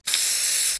spraycan_spray.wav